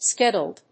音節ske・dad・dle 発音記号・読み方
/skɪdˈædl(米国英語)/